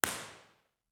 Description:  The O’Reilly Theater is a 650 seats semi-reverberant space located in the famous Pittsburg cultural district. The reverberation time is just under 1 sec, with the acoustics of the space optimized for natural support of on-stage sources.
File Type: B-Format, XY Stereo
Microphone: Core Sound Tetramic
Source: 14 sec log sweep
Test Position 2 (above)
IR_TP2_XYSTEREO_OReilly.wav